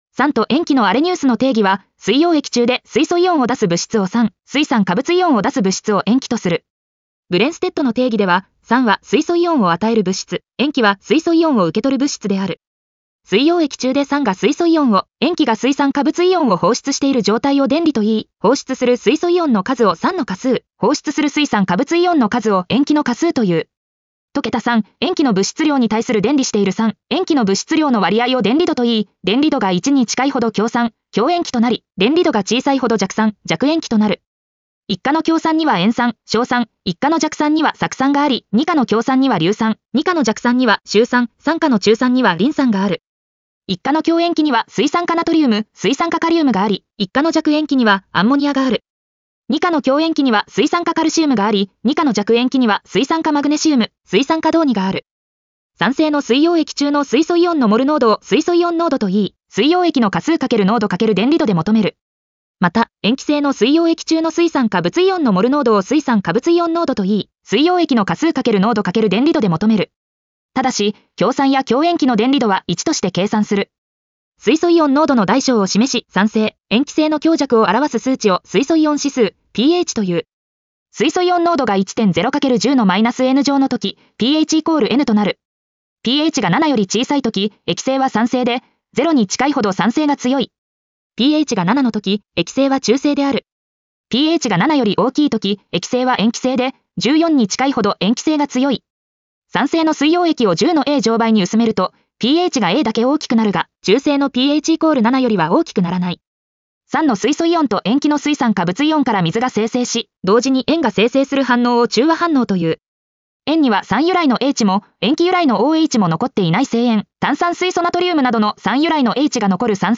ナレーション 音読さん